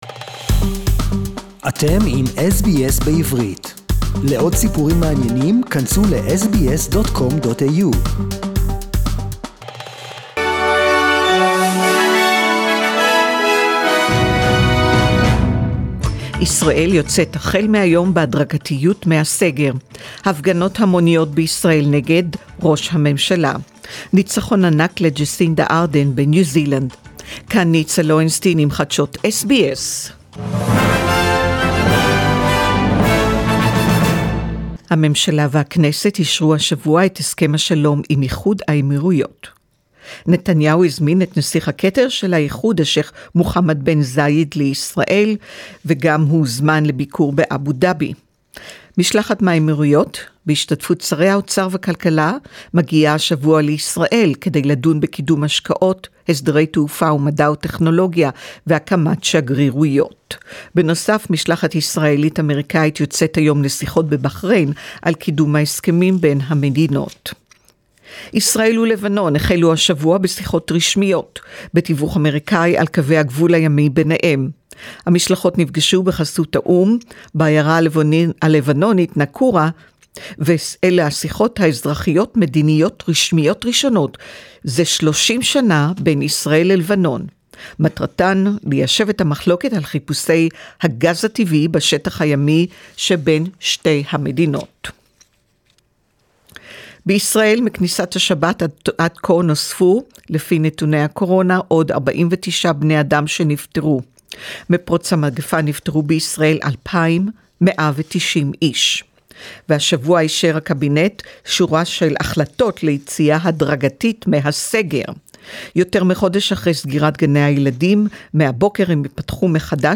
SBS NEWS IN HEBREW 18.10.2020